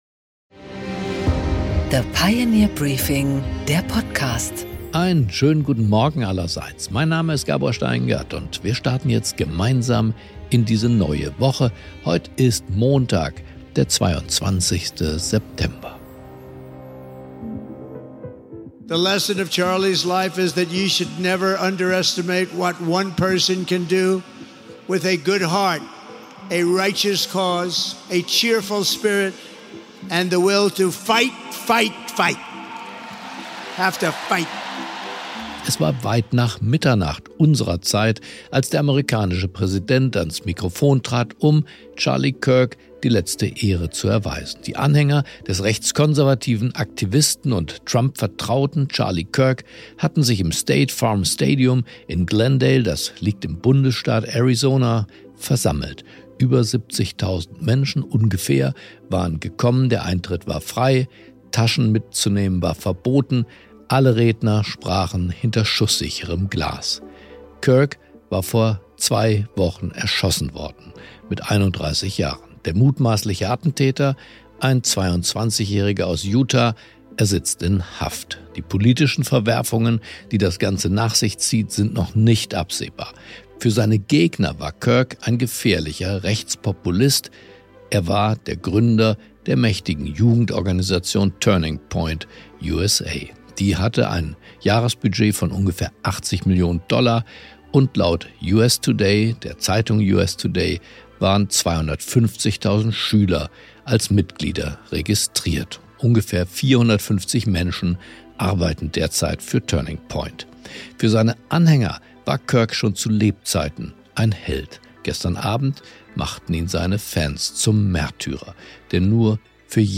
Gabor Steingart präsentiert das Pioneer Briefing
Interview mit Sigmar Gabriel